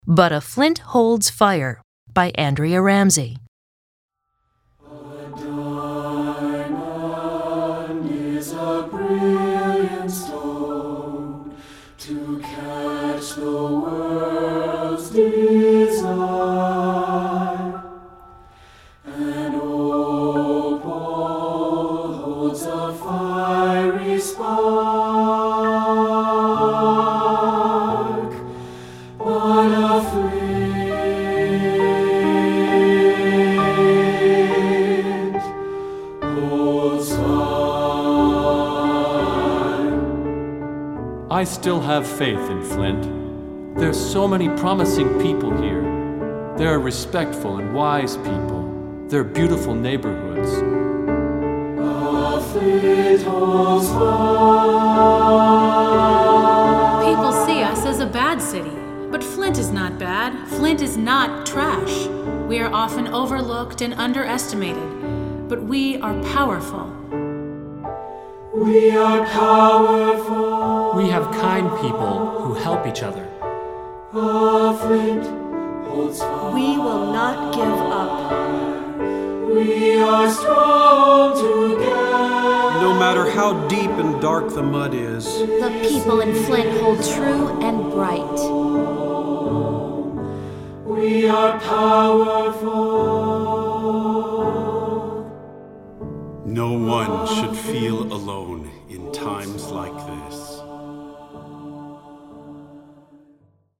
Choral Concert/General
For Unison with Narration and Piano.
Unison